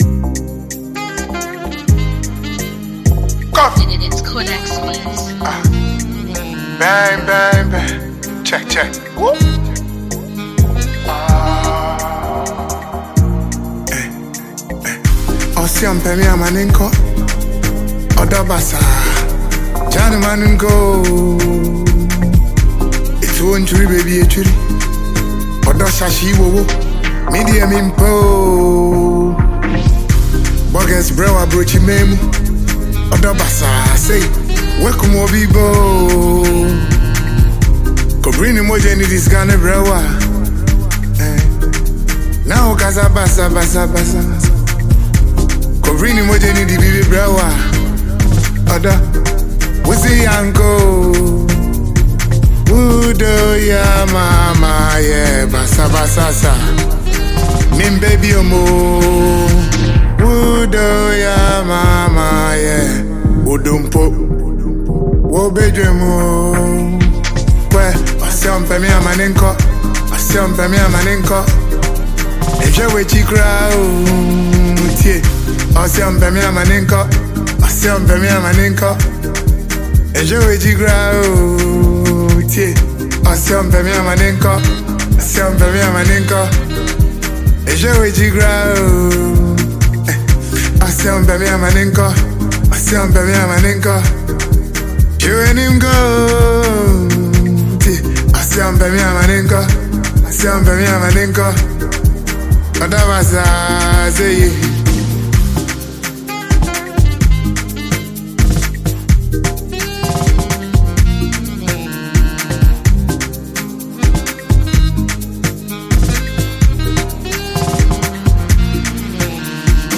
heartfelt and uplifting single